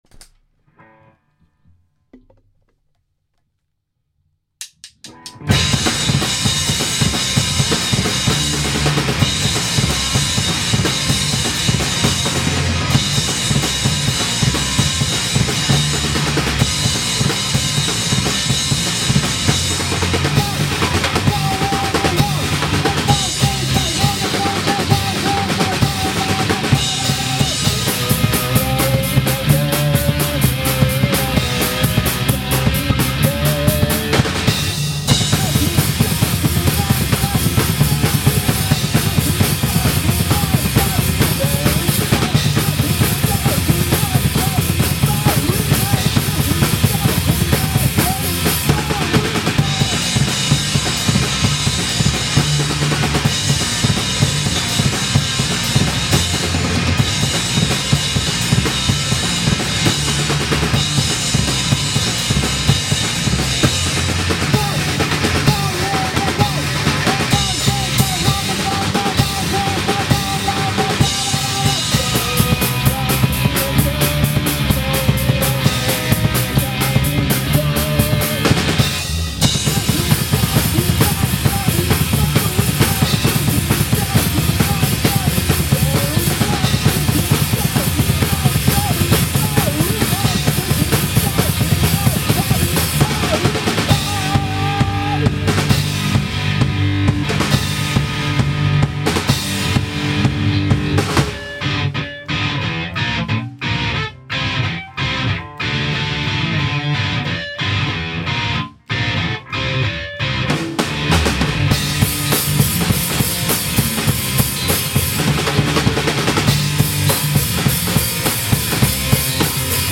メロコアなのかパンクなのか、3ピースバンド
スタジオ一発録りのDEMO音源と、今までのLIVE動画。